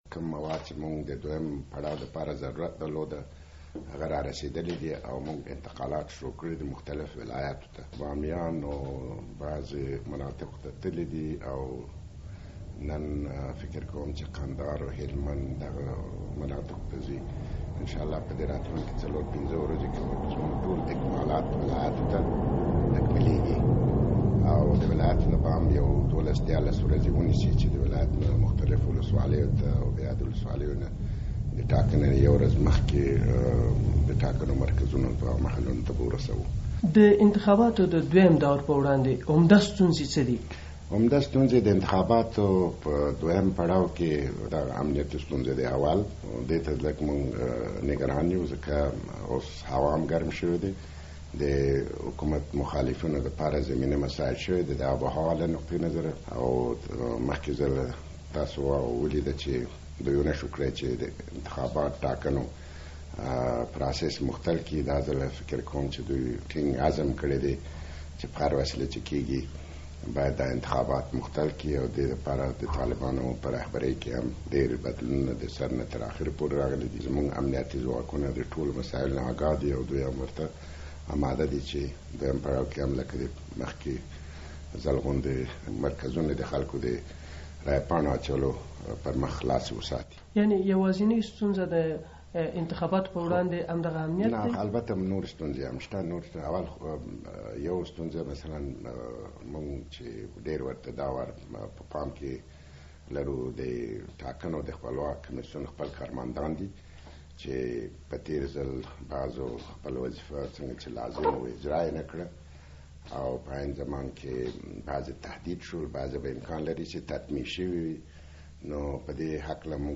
له یوسف نورستاني سره مرکه